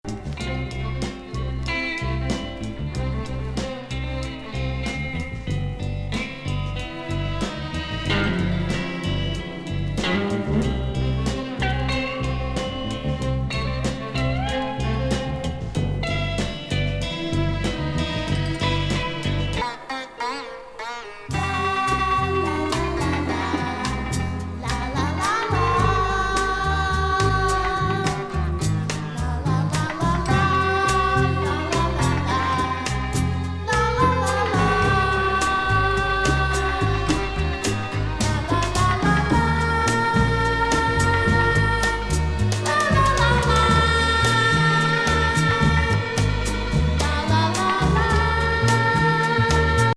白人黒人混合のサイケデリック・ソウル・グループ68年ファースト。